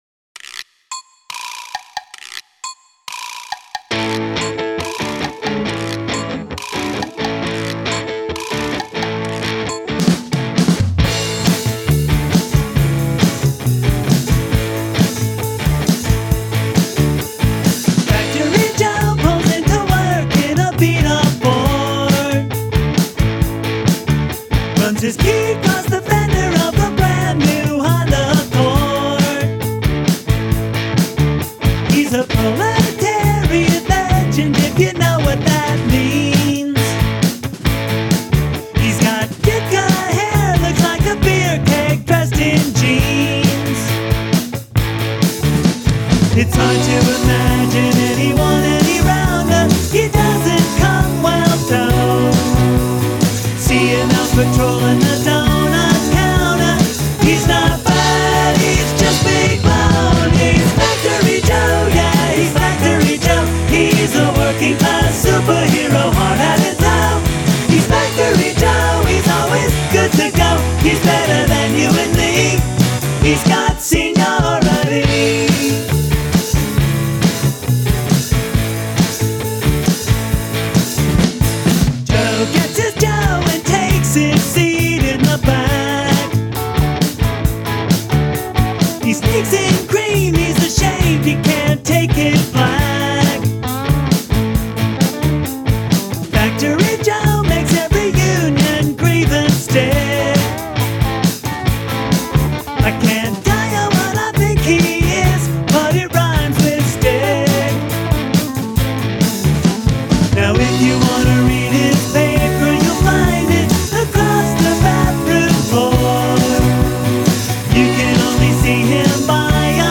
For anyone who may have heard this before - this is all new tracks except the drums.